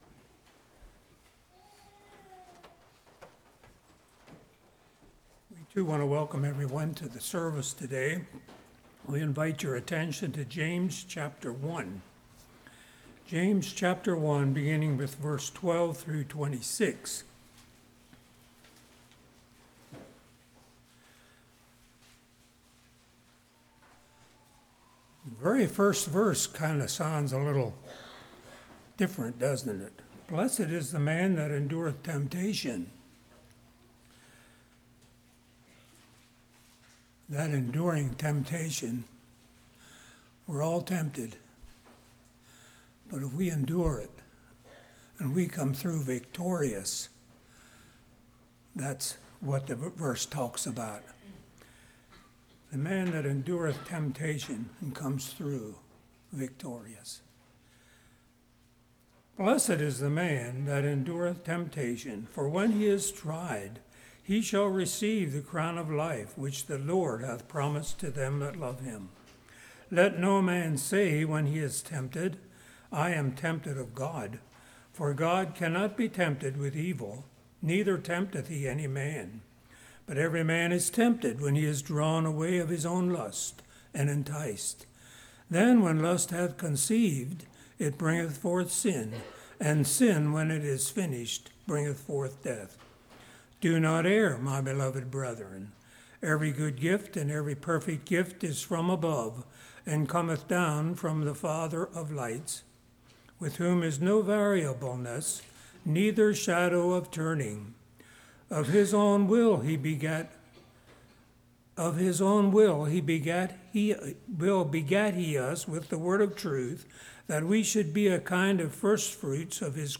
James 1:12-26 Service Type: Morning Men & Women Is It Sin?